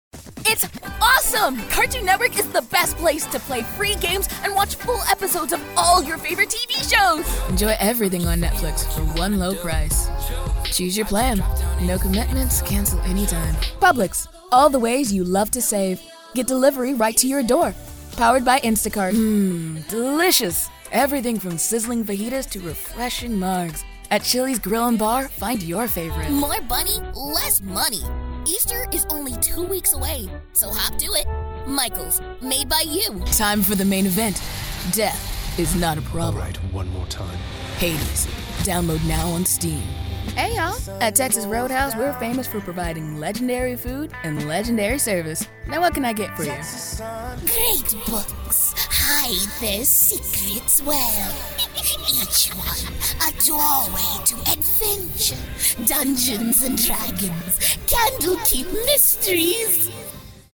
A Warm Bubby Friend
Commercial Demo
General American